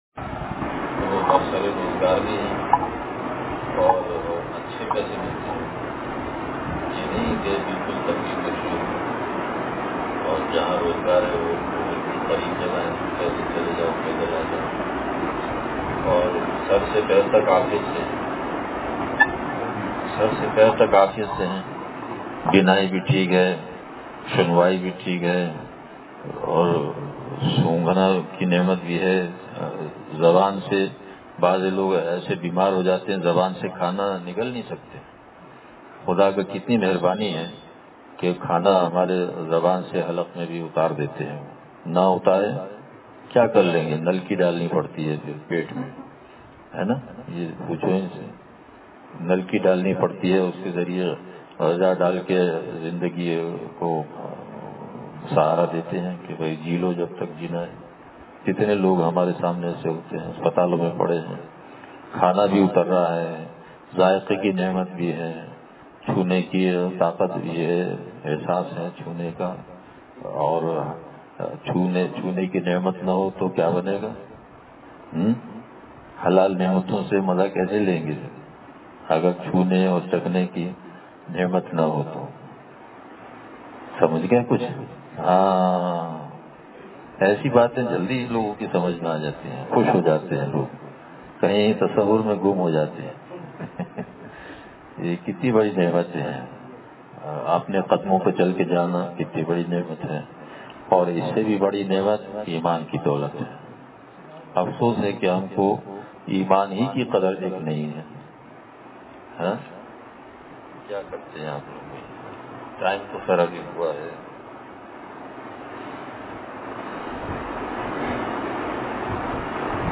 بیان